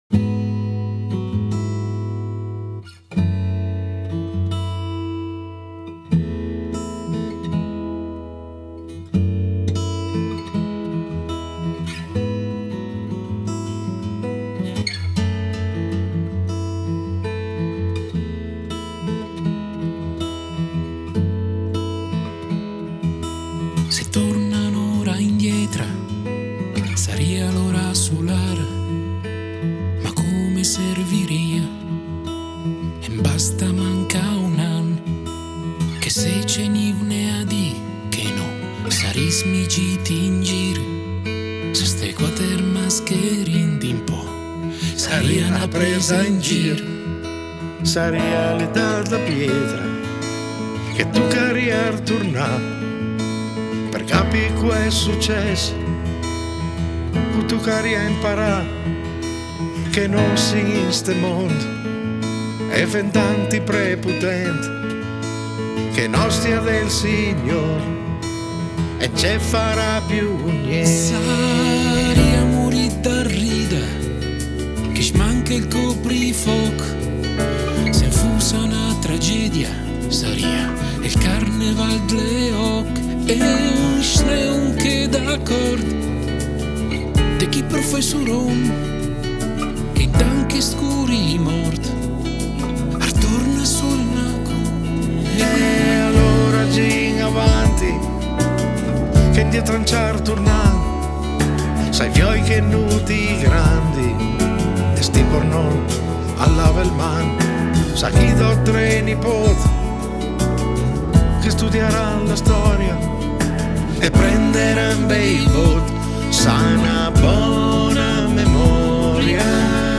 basso elettrico
piano e tastiere
chitarre elettriche